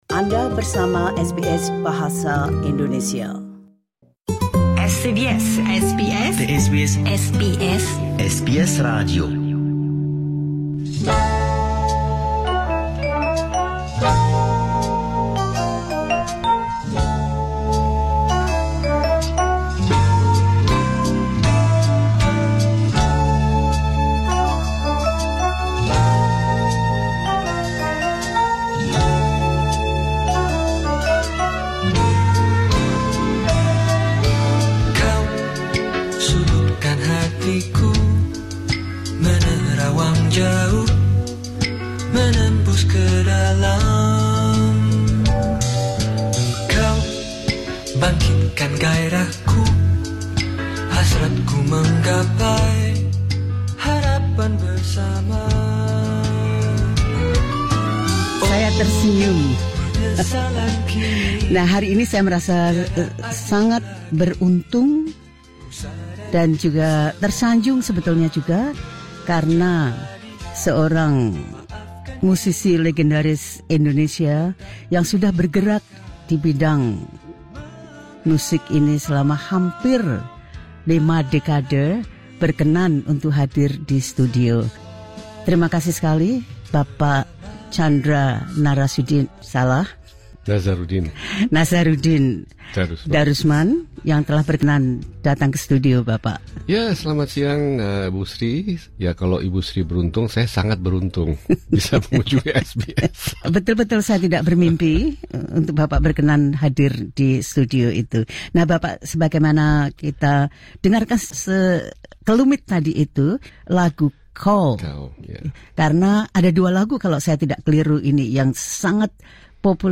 Candra Nazarudin Darusman in SBS Studio in Melbourne, 11 Feb 2026 (SBS Indonesian).